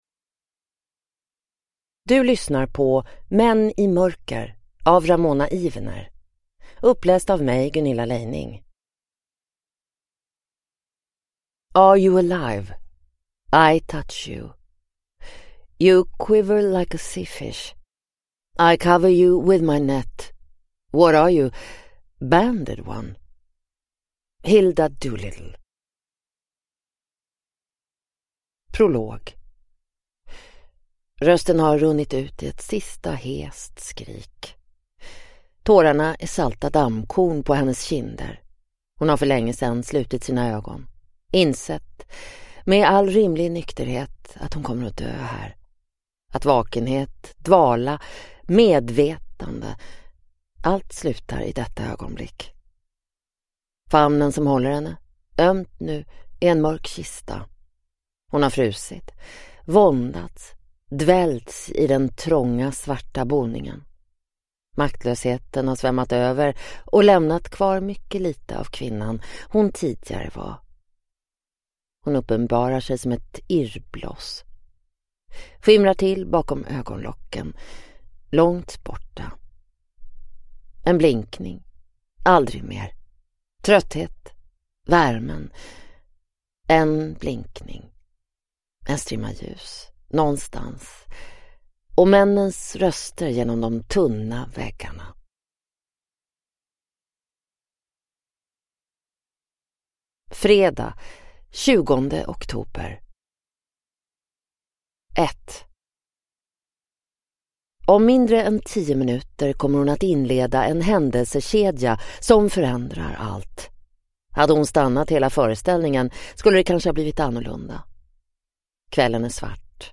Män i mörker (ljudbok) av Ramona Ivener | Bokon